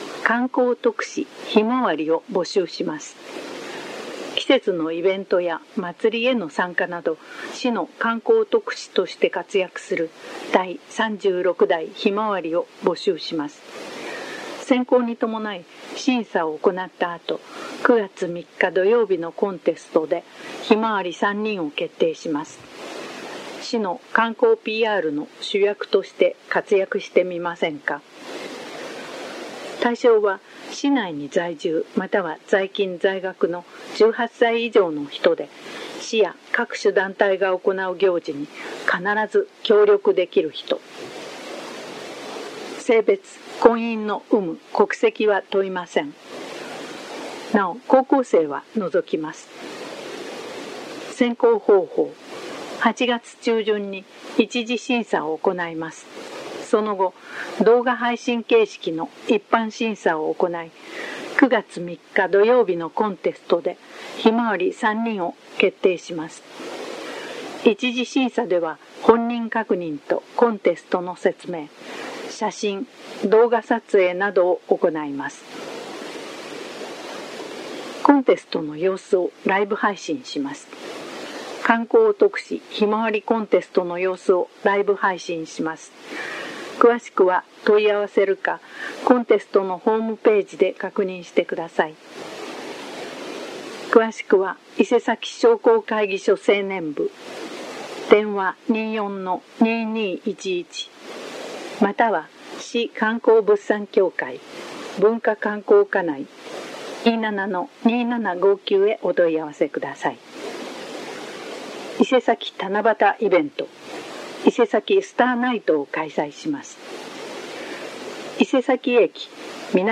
声の広報は目の不自由な人などのために、「広報いせさき」を読み上げたものです。
朗読